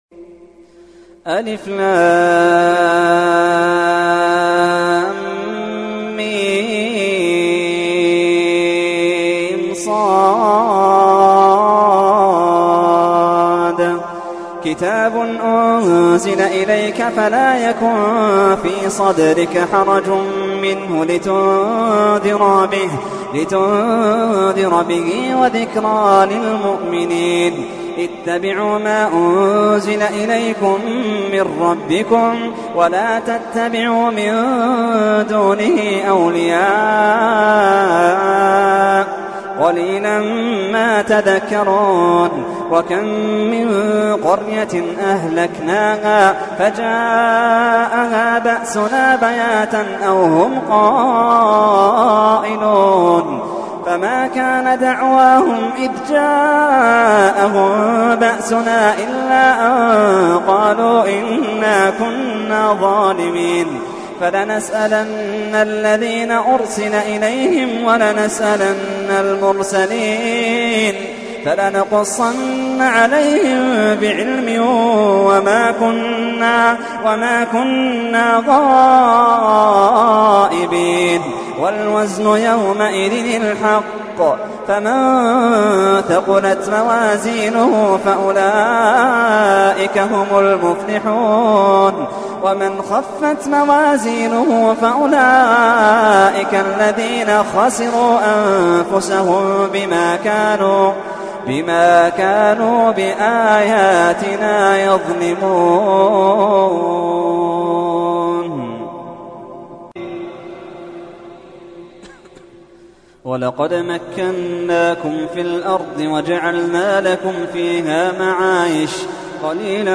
تحميل : 7. سورة الأعراف / القارئ محمد اللحيدان / القرآن الكريم / موقع يا حسين